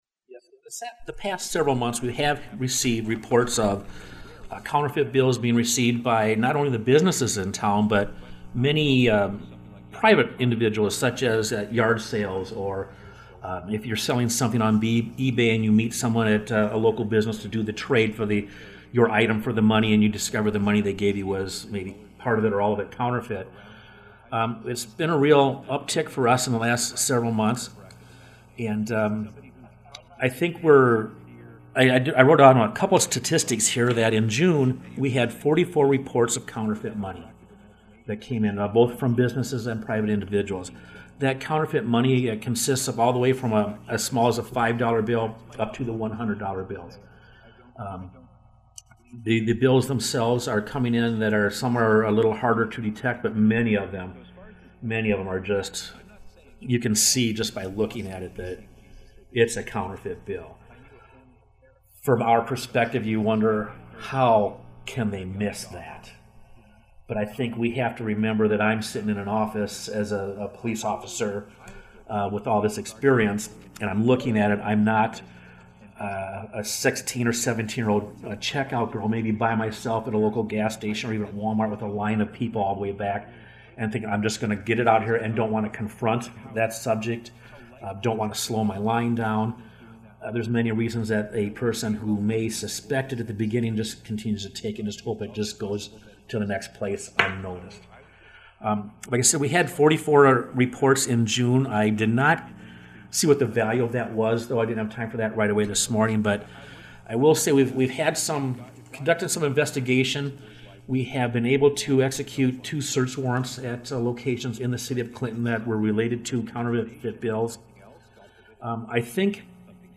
In recent weeks there have been counterfeit bills passed around the community-learn more about the issue and the progress the CPD have made in the cases in this conversation